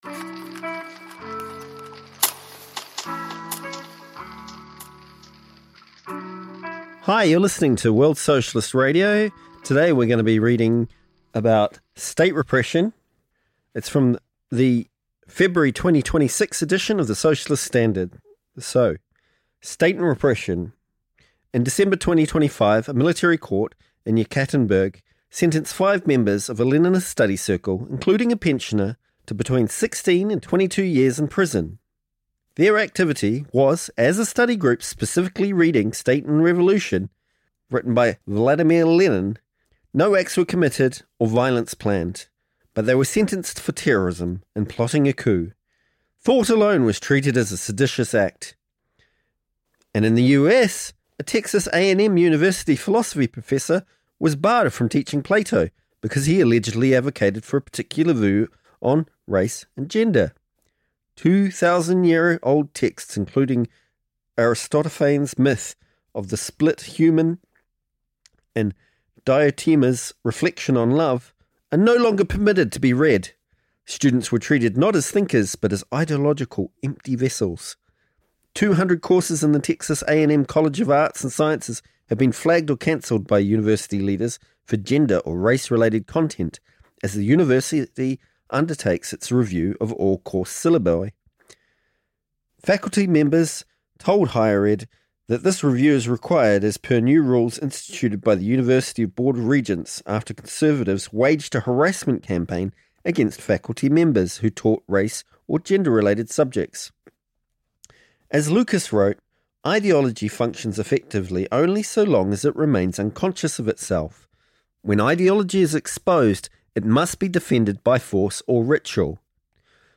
Featuring music: 'Pushing P (Instrumental)' by Tiga Maine x Deejay Boe.